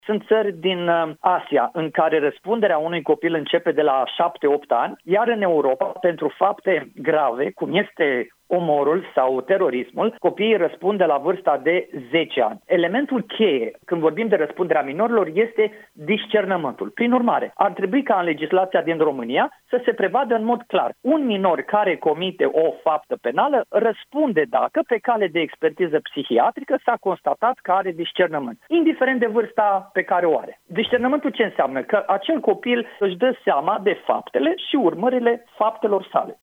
Cristi Danileț, fost judecător: „Ar trebui ca în legislația din România să se prevadă în mod clar: un minor care comite o faptă penală, răspunde dacă pe cale de expertiză psihiatrică s-a constatat că are discernământ”